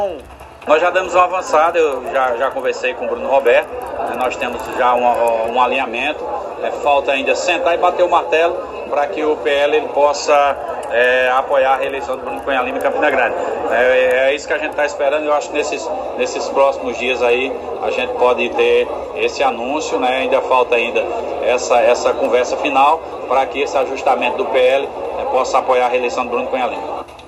Os comentários do deputado foram registrados durante o programa Arapuan Verdade, da Rádio Arapuan FM.